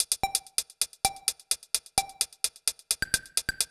TEC Beat - Mix 11.wav